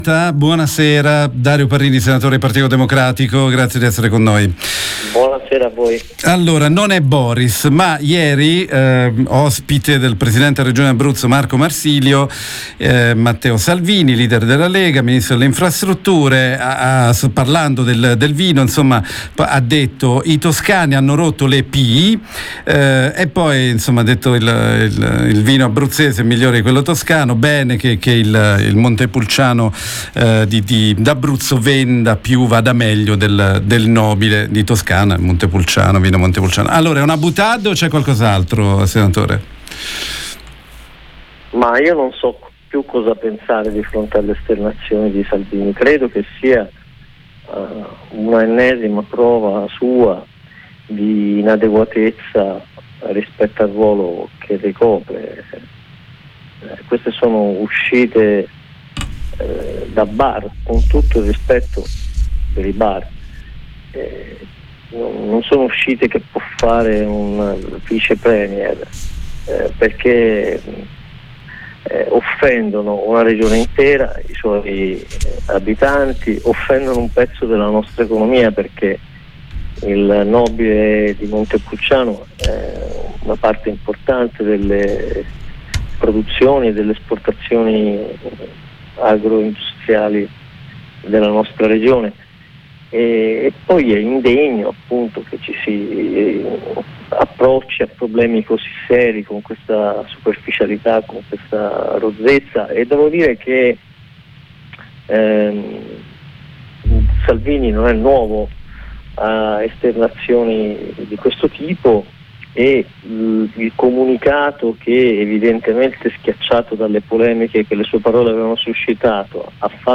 Lo abbiamo intervistato